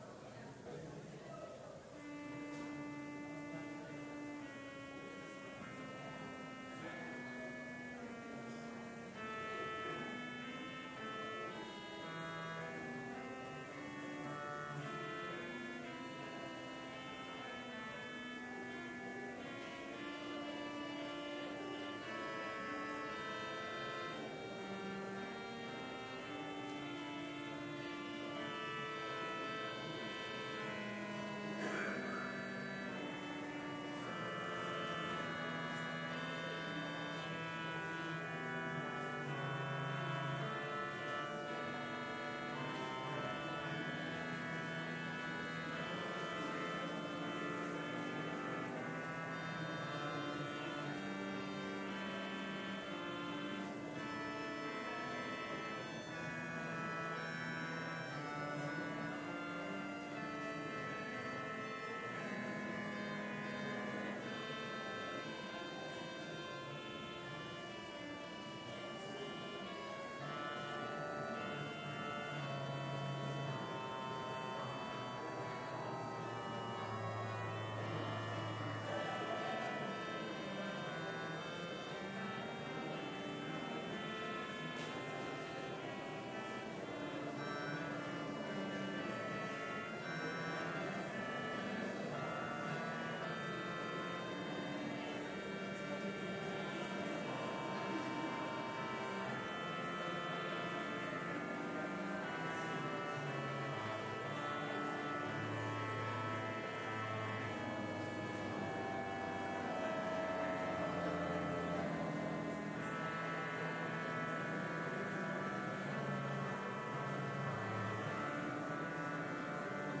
Complete service audio for Chapel - February 1, 2019